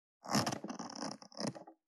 423,ジッパー,チャックの音,洋服関係音,
ジッパー